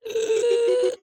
Minecraft Version Minecraft Version snapshot Latest Release | Latest Snapshot snapshot / assets / minecraft / sounds / mob / strider / retreat1.ogg Compare With Compare With Latest Release | Latest Snapshot